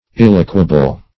Search Result for " illaqueable" : The Collaborative International Dictionary of English v.0.48: Illaqueable \Il*la"que*a*ble\, a. Capable of being insnared or entrapped.
illaqueable.mp3